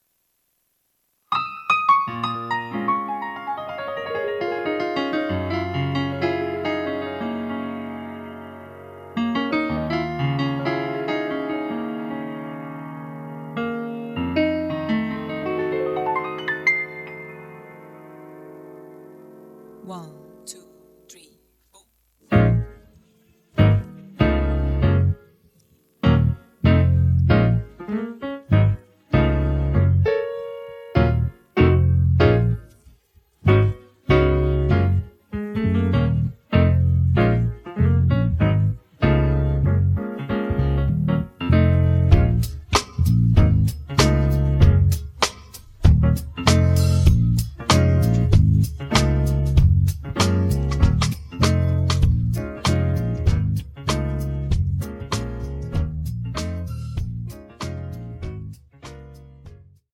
음정 -1키 3:24
장르 가요 구분 Voice Cut